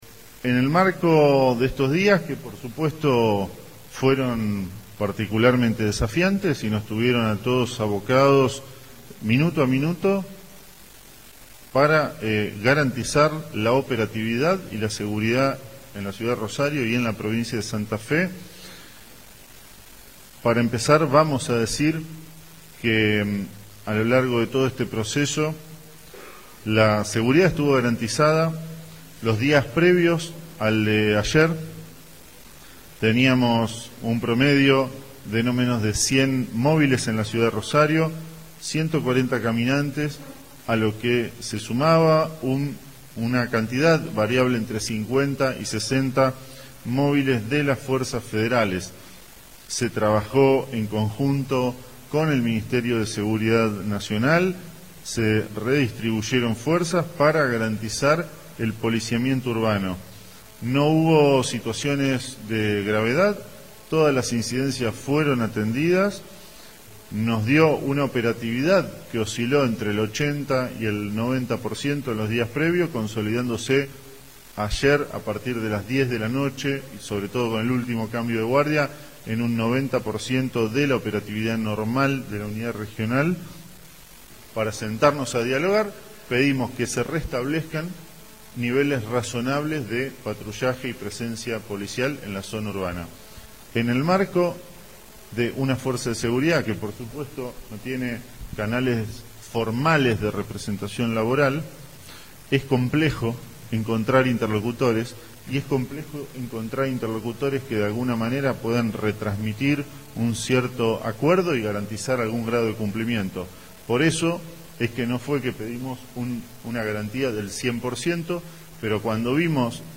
Los ministros de Justicia y Seguridad, Pablo Cococcioni, y de Economía, Pablo Olivares, brindaron este miércoles una actualización sobre la situación derivada del conflicto policial y anunciaron decisiones orientadas a normalizar el servicio, recomponer el vínculo con la fuerza y atender reclamos salariales considerados legítimos.
Conferencia de prensa